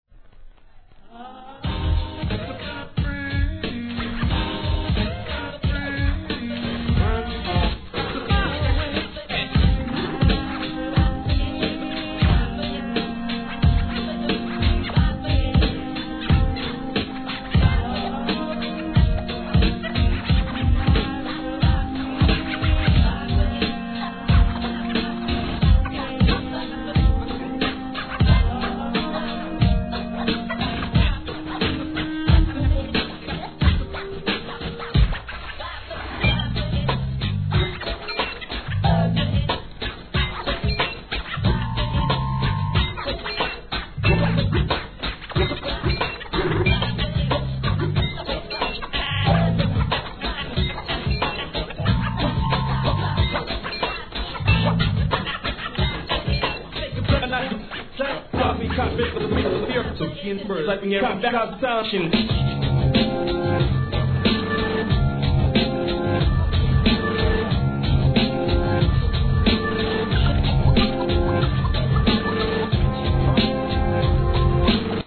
HIP HOP/R&B
数々のサンプリング音源を次々にMEGA MIXするノンSTOP LIVE音源!!